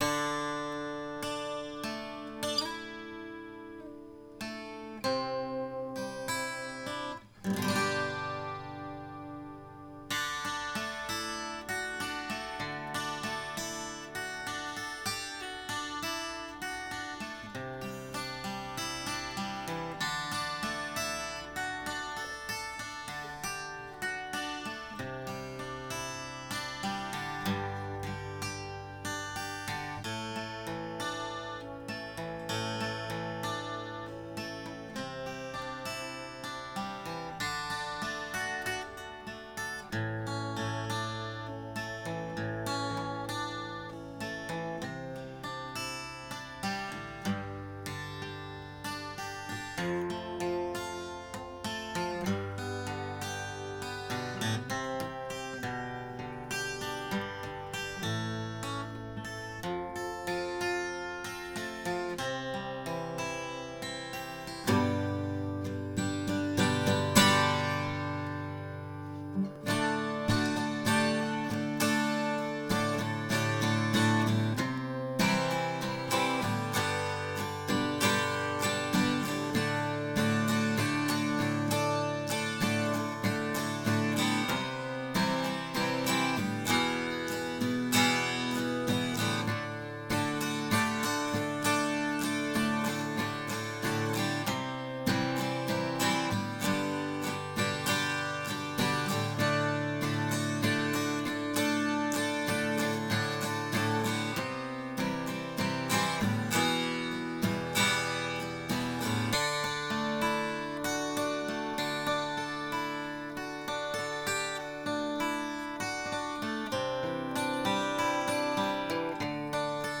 music_smorgasbord_overalifetime_acoustic_R.m4a